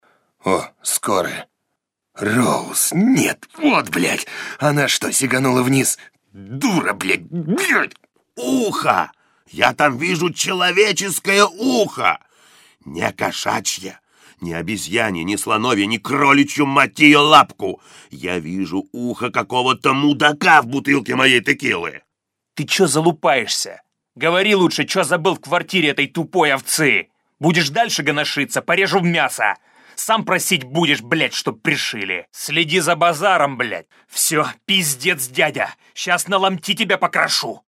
Лексика в игре соответствующая — её образец можно послушать ниже.
ВНИМАНИЕ! Не слушайте данный файл, если вам неприятна нецензурная лексика, если вы беременны или если вам не исполнилось 18 лет.
Примеры озвучения: